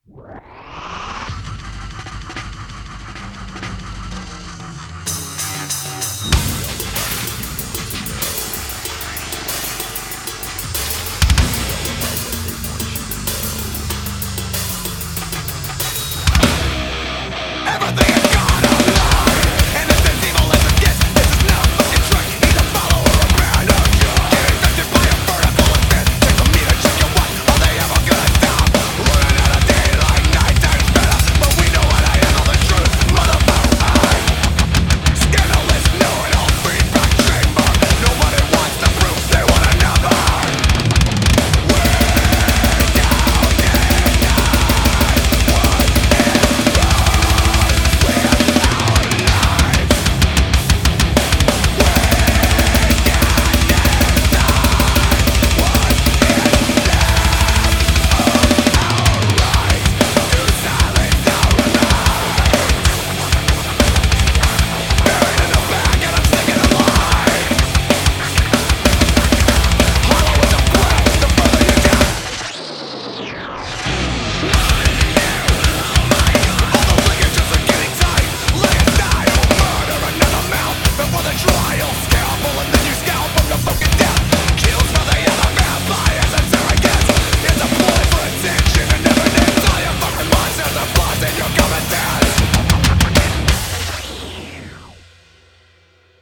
最具侵略性的鼓库